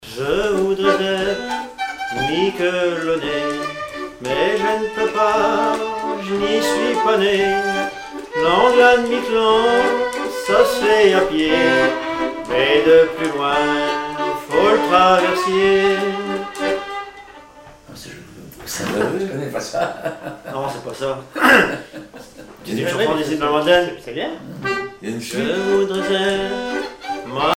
danse : valse
violon
Pièce musicale inédite